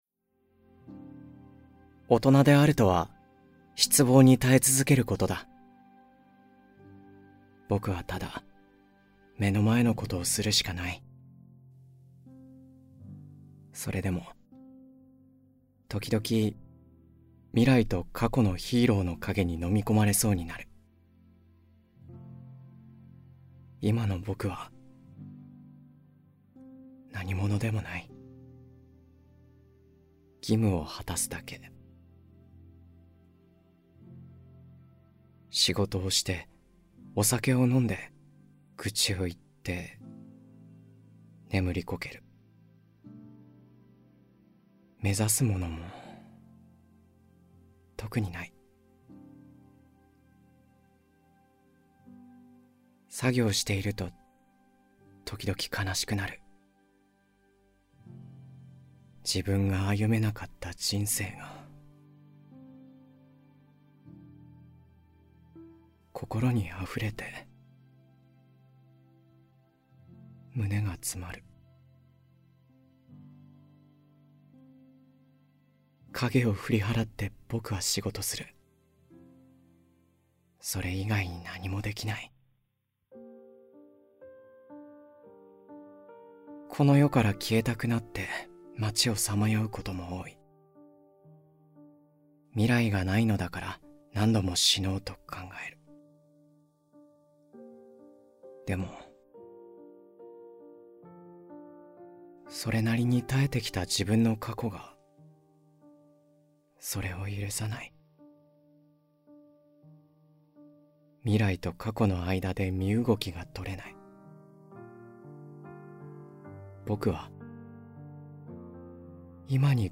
[オーディオブック] ぼくはヒーローに、なれなかった。